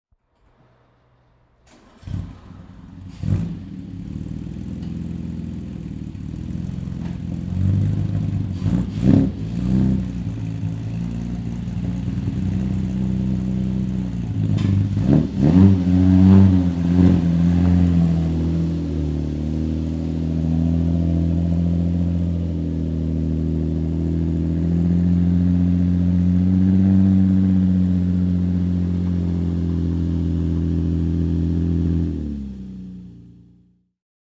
Atalanta Sport Tourer (1938) - Starting and idling